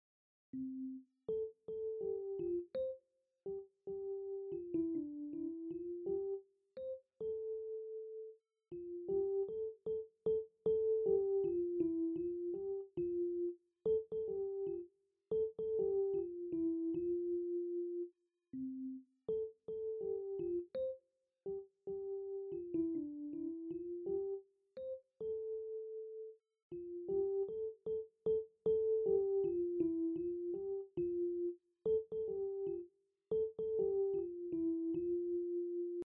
A ring tone, which sounds as if coming from another dimension, is heard from every one of the group’s phones.
Soon-aes-ringtone-part-1.mp3